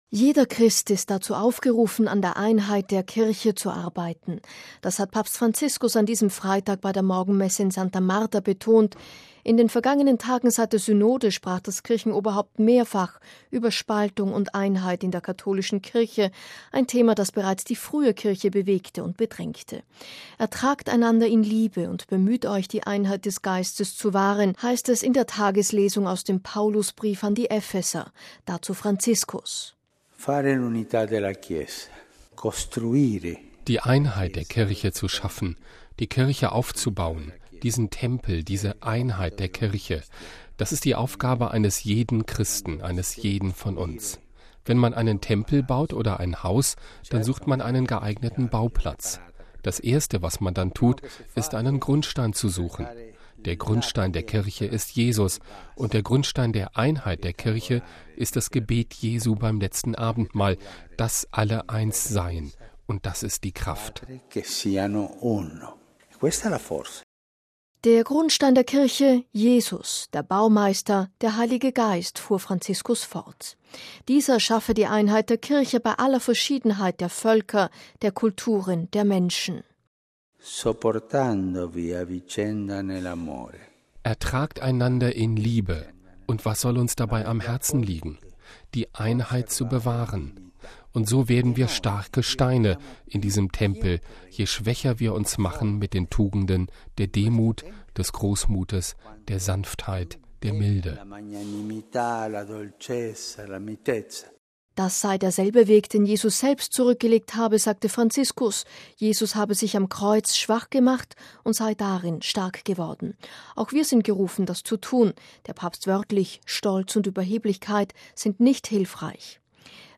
Papstpredigt: Von den Bauplänen der Kirche
MP3 Jeder Christ ist dazu aufgerufen, an der Einheit der Kirche zu arbeiten. Das hat Papst Franziskus an diesem Freitag bei der Morgenmesse in Santa Marta betont; in den vergangenen Tagen seit der Synode sprach das Kirchenoberhaupt mehrfach über Spaltung und Einheit in der katholischen Kirche – ein Thema, das bereits die frühe Kirche bewegte und bedrängte.